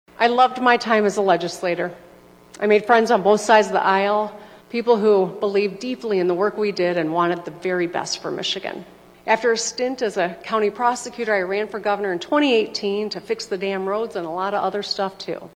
Whitmer called for political unity and had a human and relatable tone during her 48-minute speech.